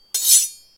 Other Sound Effects
sword.7.ogg